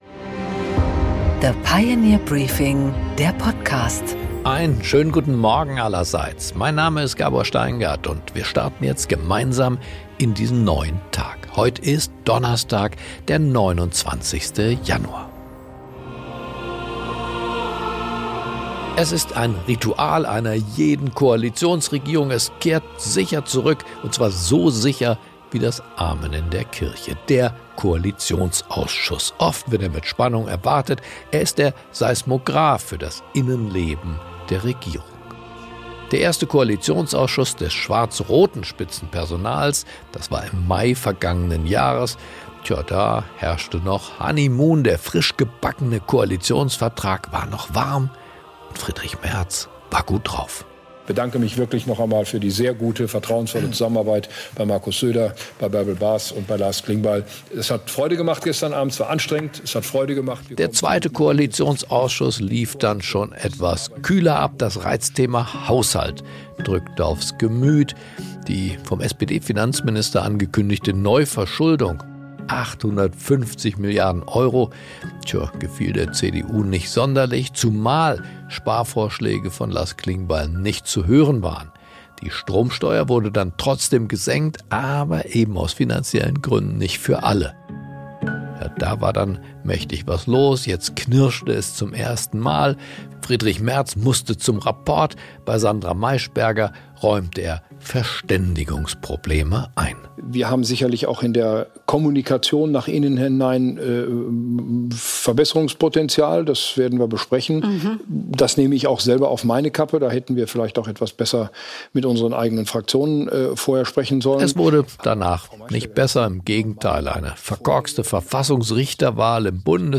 Gabor Steingart präsentiert das Pioneer Briefing.
Im Gespräch: Im „Kapitalmarkt‑Briefing“ erklärt Ex‑Finanzminister und früherer Deutschland‑Chef von Goldman Sachs, Jörg Kukies, warum ein Freihandelsabkommen mit Indien für Europa so wichtig ist, wieso Gold trotz Null‑Dividende als sicherer Hafen Rekordpreise erreicht und wie riskant sogenannte Carry Trades werden können, wenn sich Währungsrelationen drehen.